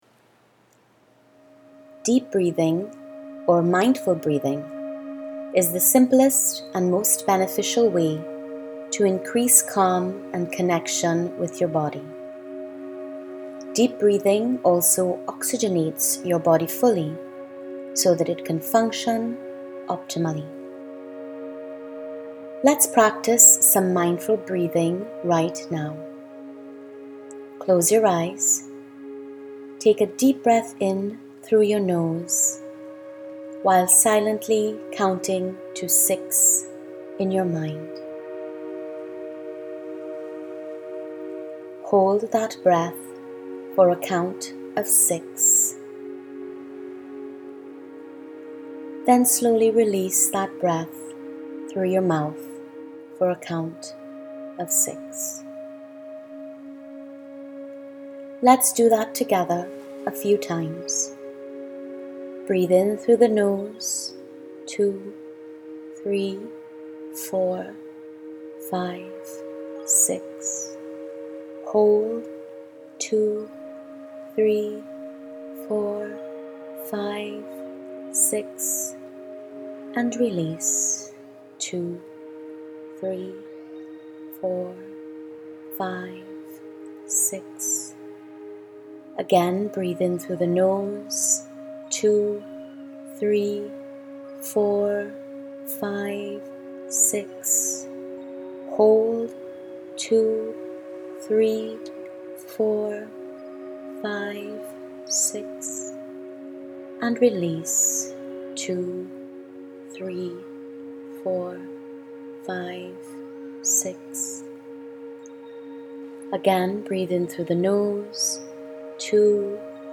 Deep Breathing Exercise
01-Deep-Breathing-Exercise.mp3